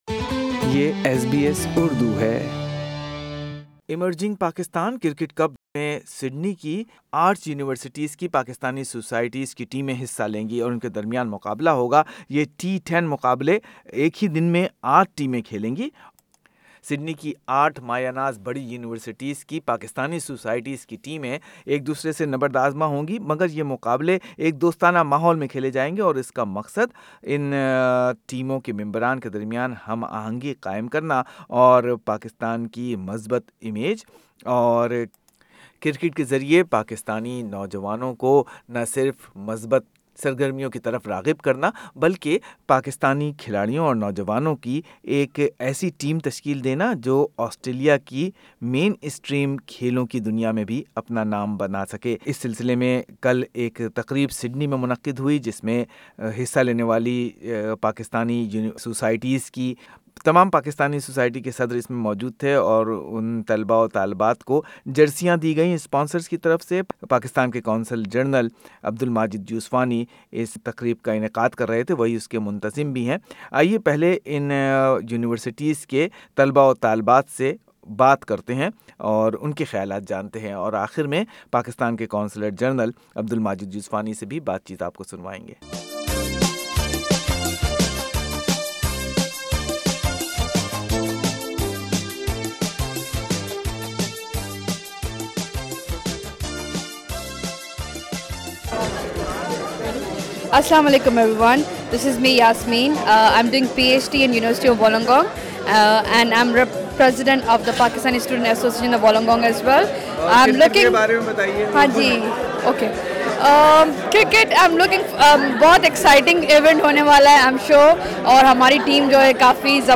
Listen what Pakistani Student associations leaders and CG Pakistan say about this unique tournament during tournament launch event.